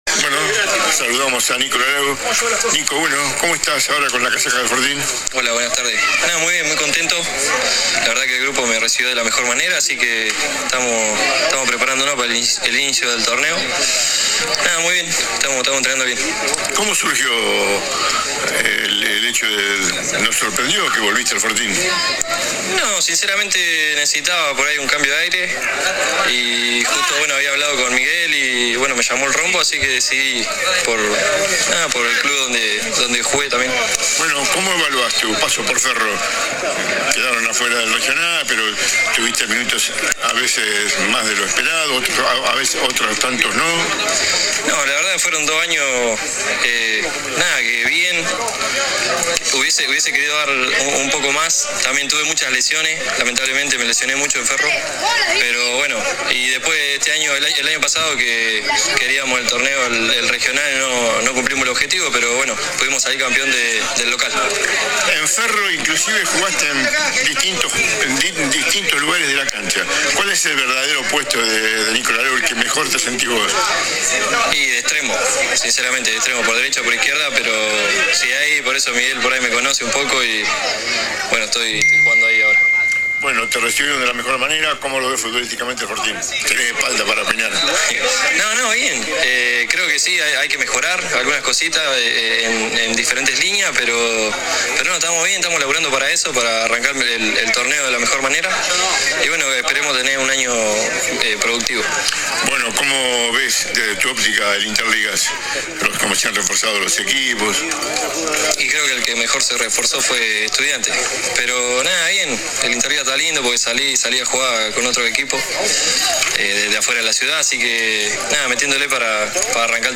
Finalmente mostró su entusiasmo por el Interligas que se viene, y en cuanto al Regional Federal Amateur dudó de lo deportivo y destacó lo hecho por Racing. AUDIO DE LA ENTREVISTA https